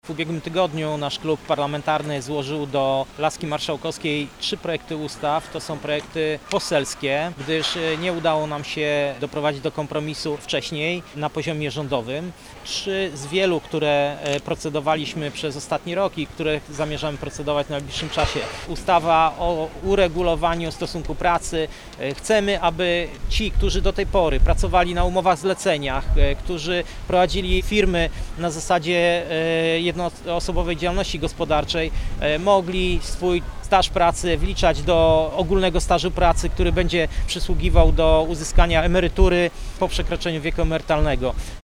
-W najbliższym czasie zamierzamy procedować kolejne ustawy, bardzo ważną kwestią są przepisy o uregulowaniu stosunku pracy – dodaje Arkadiusz Sikora, Poseł na Sejm RP, współprzewodniczący Lewicy na Dolnym Śląsku.